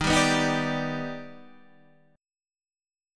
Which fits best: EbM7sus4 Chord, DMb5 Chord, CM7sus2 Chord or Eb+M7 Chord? Eb+M7 Chord